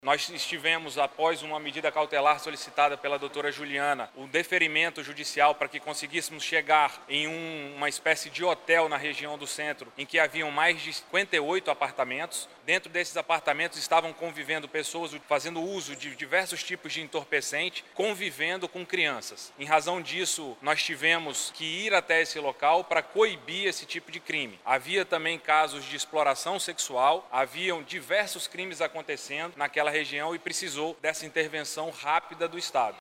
O delegado-geral da Polícia Civil do Amazonas – PC/AM, Bruno Fraga, explica que a operação foi deflagrada após uma denúncia de que no local, menores viviam em meio à práticas ilegais.